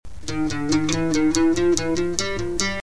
Sol#,Do7,Do#,